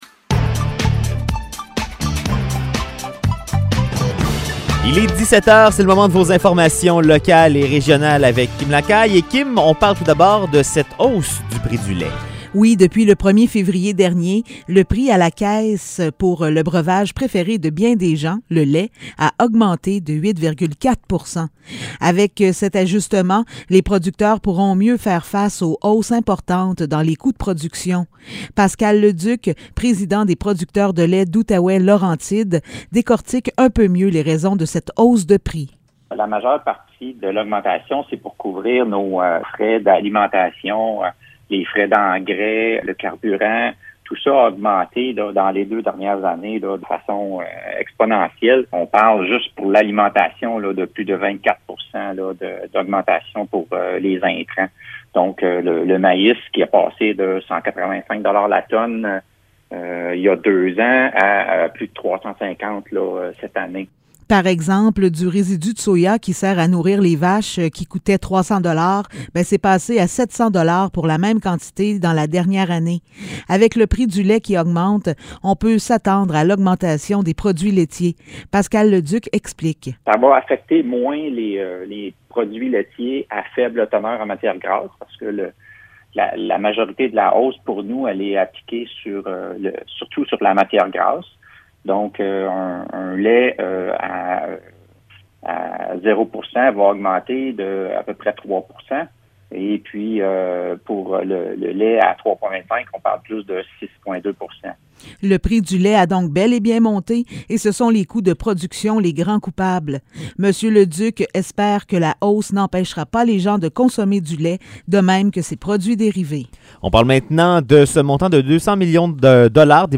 Nouvelles locales - 7 février 2022 - 17 h